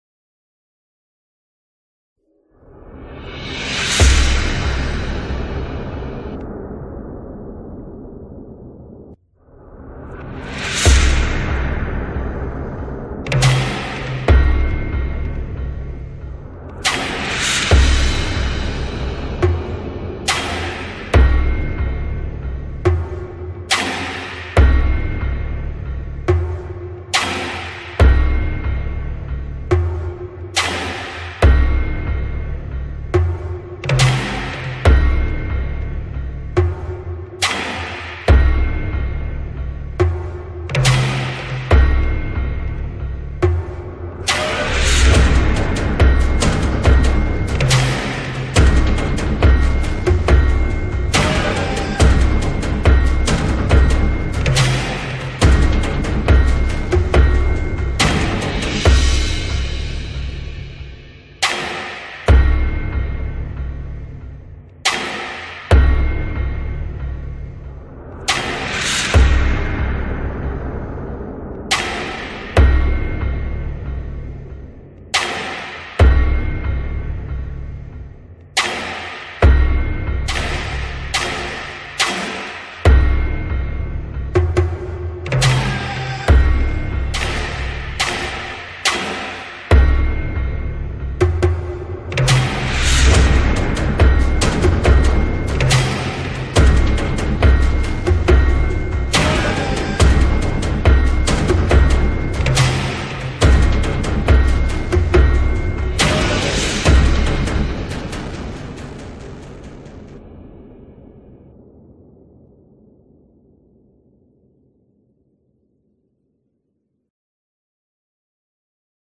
سرودهای امام حسین علیه السلام
بی‌کلام